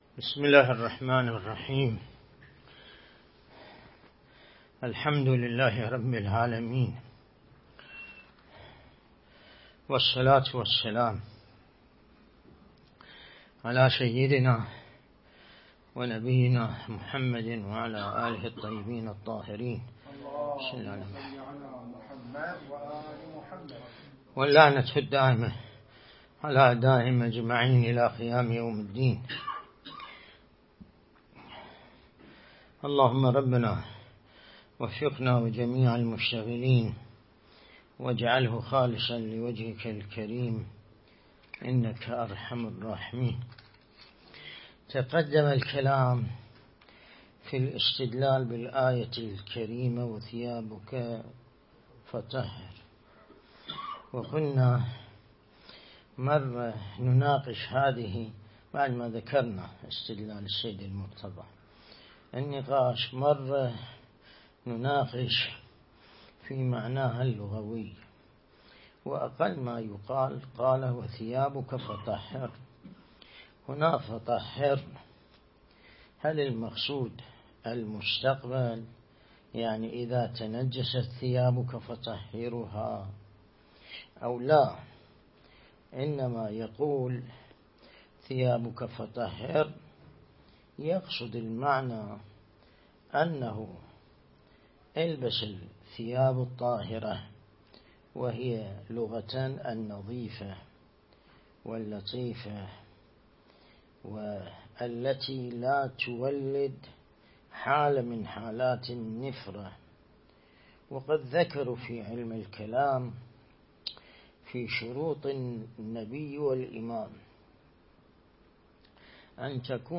درس (53) | الدرس الاستدلالي شرح بحث الطهارة من كتاب العروة الوثقى لسماحة آية الله السيد ياسين الموسوي(دام ظله)